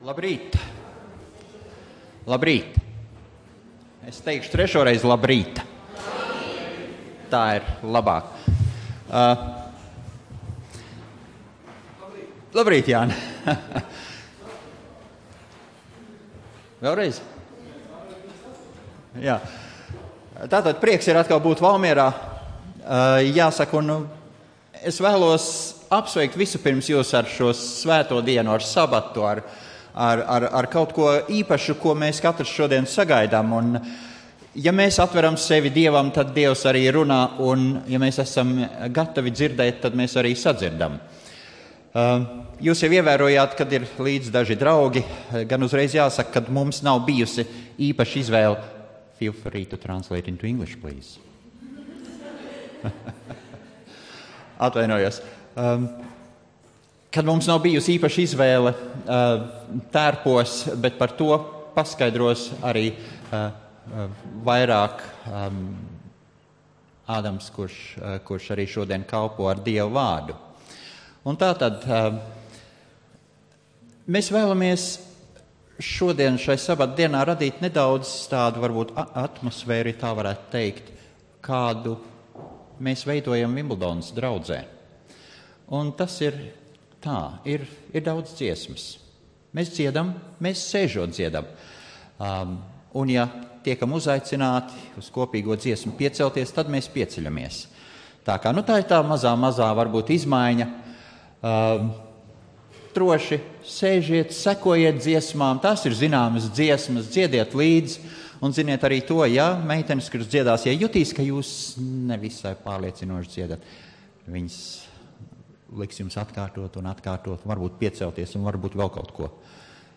Svētrunas